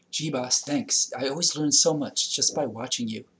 Prosodic Patterns in English Conversation
audio examples for Chapter 7: Expressing Positive Assessment